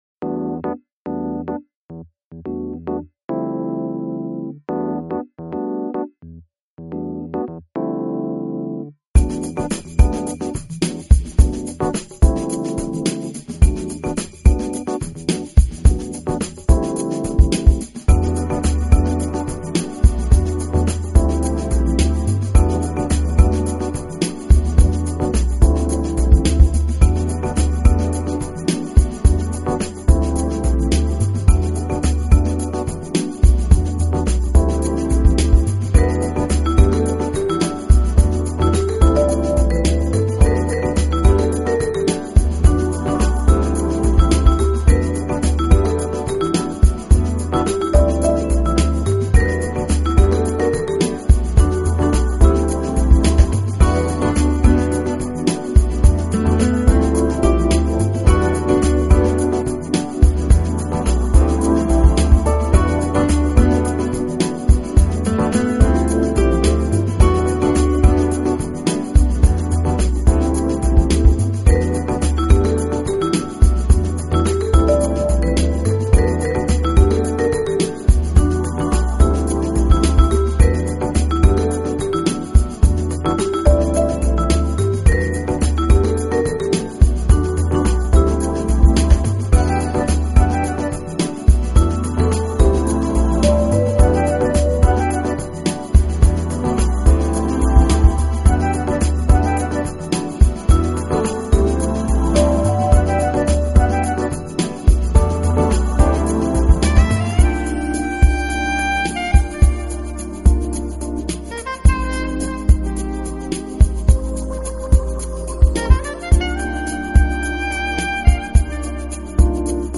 Genre: Jazz, Smooth Jazz